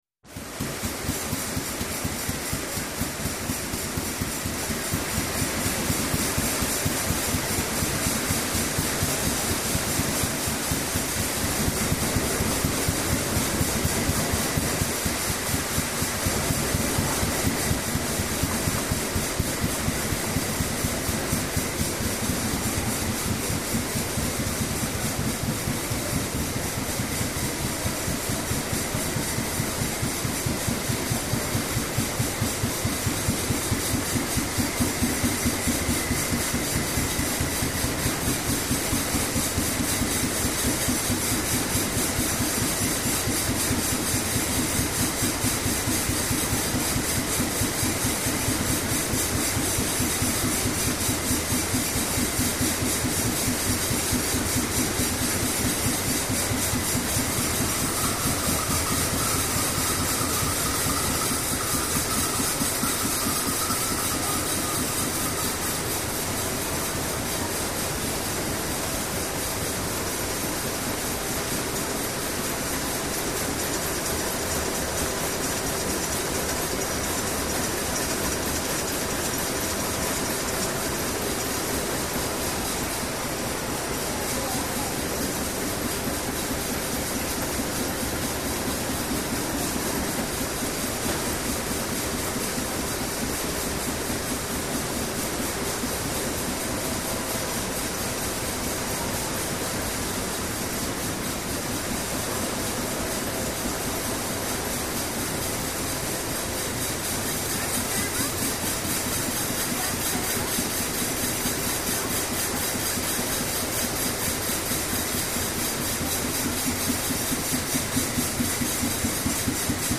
Canning Machine Steady Chug With Metal Edge.